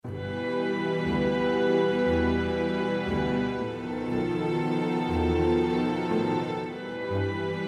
Strings Adagio, atmospheric and brooding